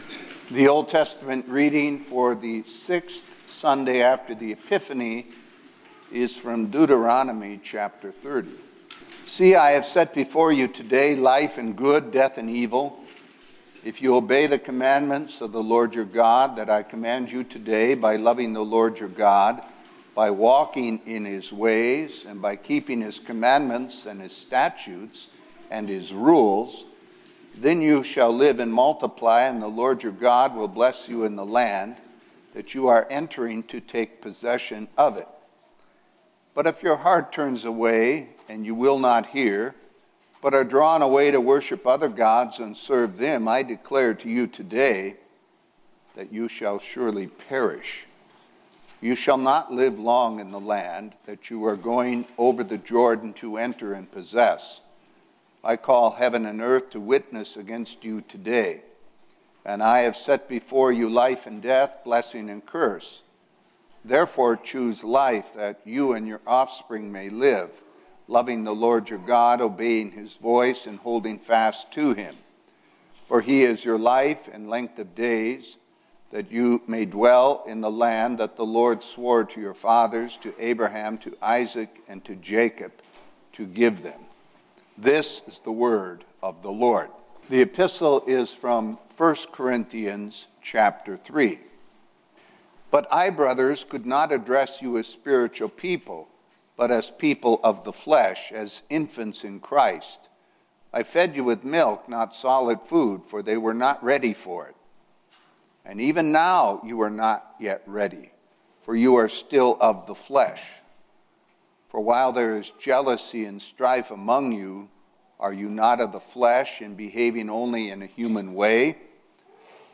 Word & Sermon Weekly – Sixth Sunday after the Epiphany – 02/12/2023